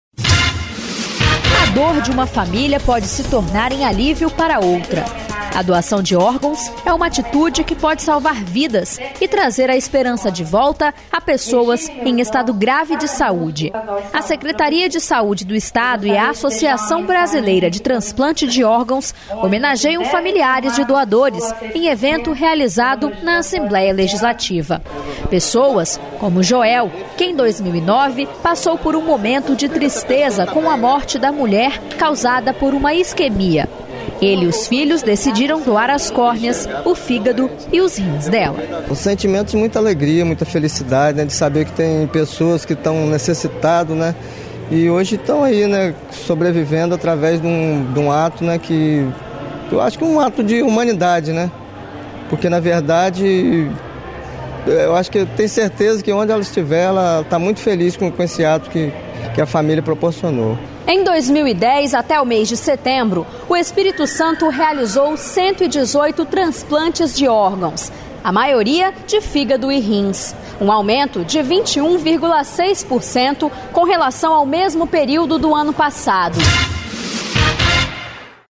Programa diário com reportagens, entrevistas e prestação de serviços
Notícias da Assembleia Legislativa do Espírito Santo